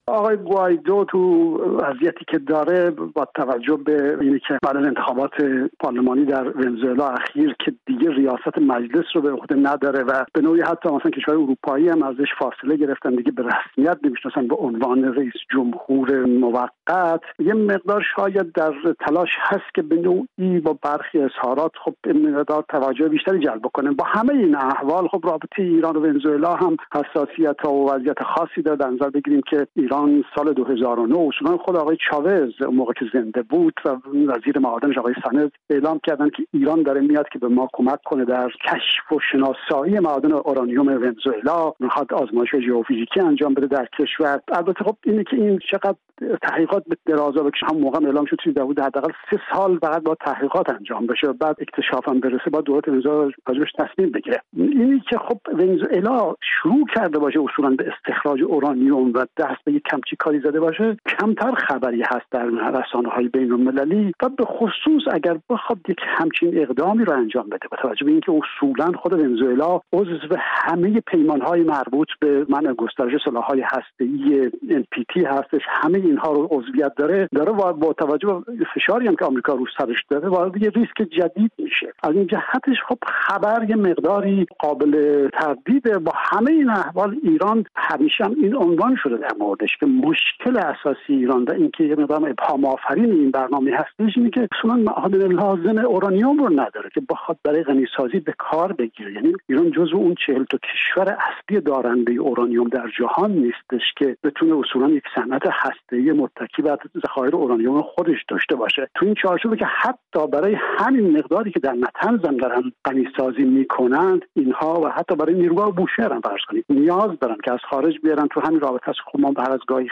تحلیلگر سیاسی ساکن آلمان